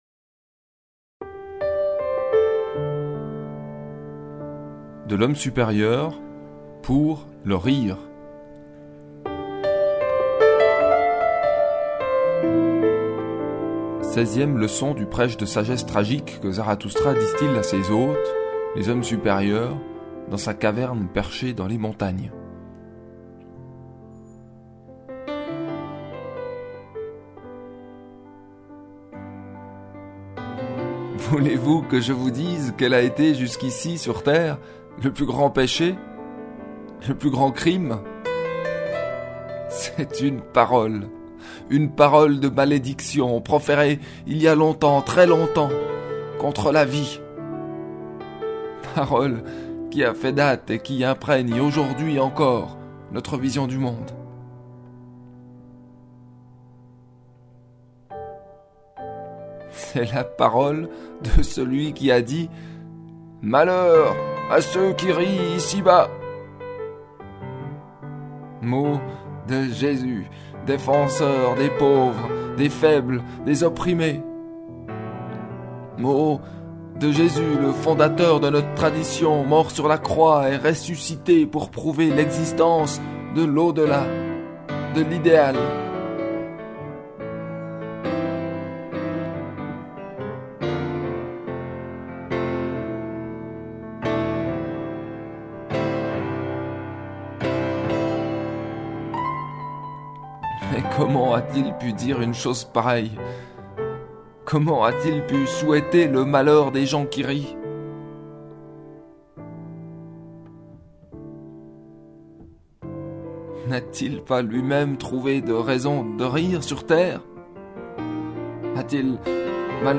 Musique : Keith Jarrett, Köln Concert, 1975.